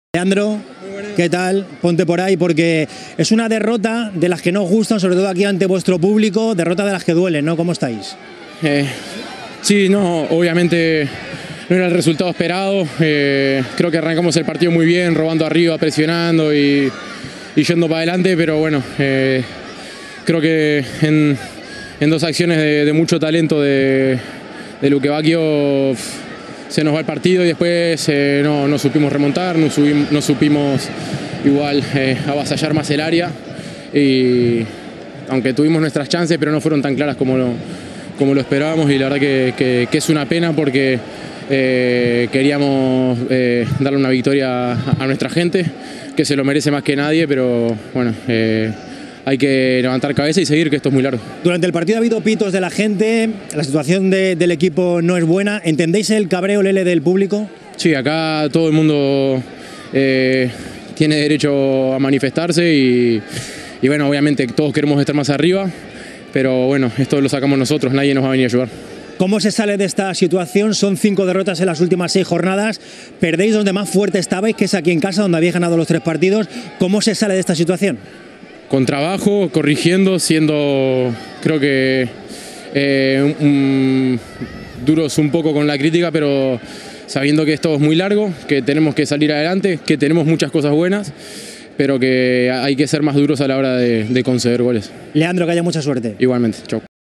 Leandro Cabrera ha valorado para las cámaras de las televisiones con derechos el RCD Espanyol – Sevilla FC disputado este viernes en el RCDE Stadium, que se ha saldado con una nueva derrota de los blanquiazules, la quinta en los últimos seis partidos.